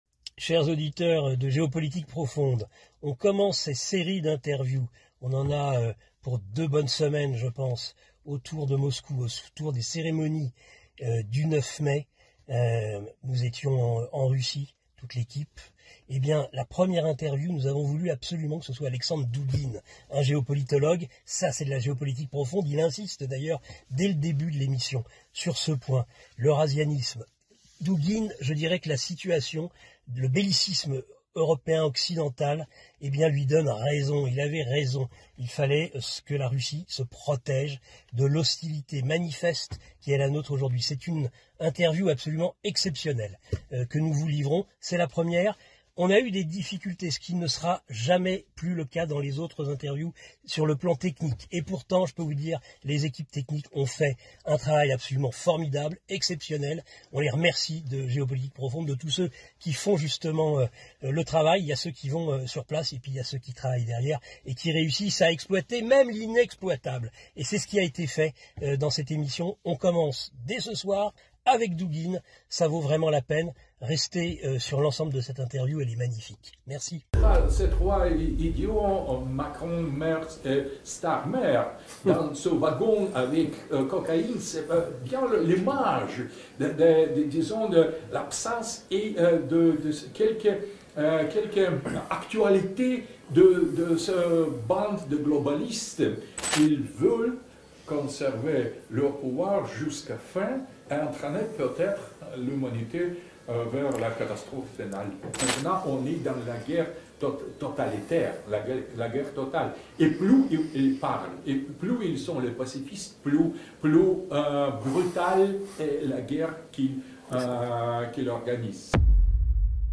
INTERVIEW À MOSCOU : L’ÉLITE COCAÏNÉE NOUS AMÈNE À LA CATASTROPHE FINALE ! | ALEXANDRE DOUGUINE